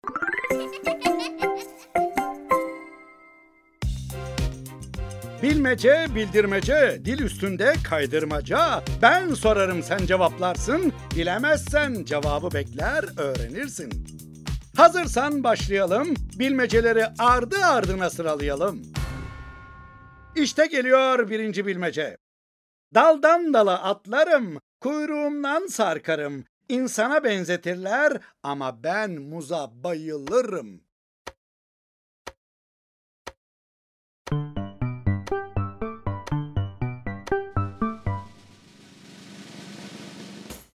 Bilmeceler-4 Tiyatrosu